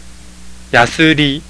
有聲發音